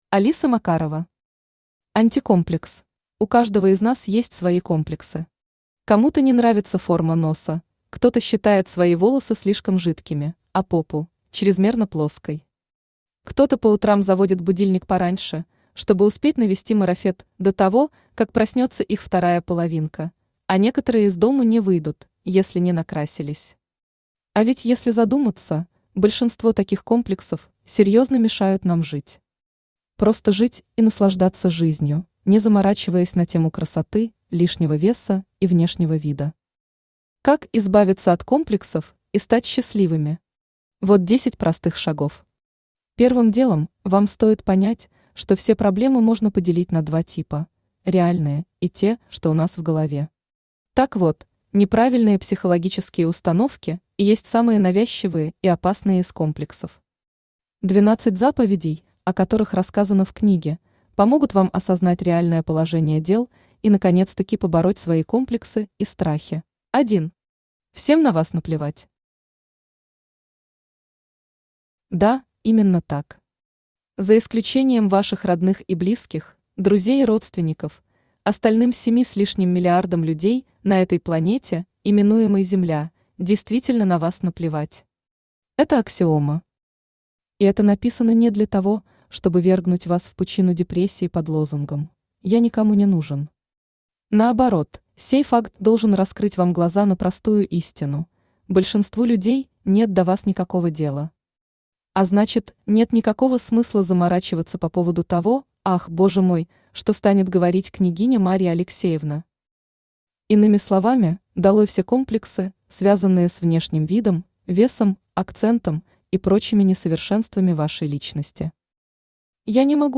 Аудиокнига Антикомплекс | Библиотека аудиокниг
Aудиокнига Антикомплекс Автор Алиса Макарова Читает аудиокнигу Авточтец ЛитРес.